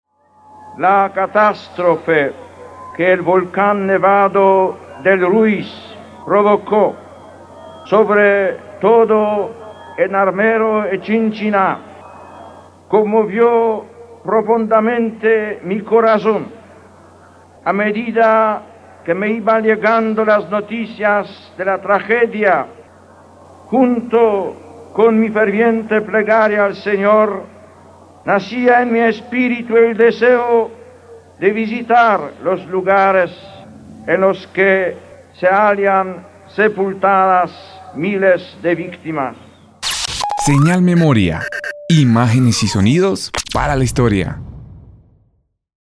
Juan Pablo II - Saludo y oración en Armero (7 Jul 1986) Audio Señal Memoria.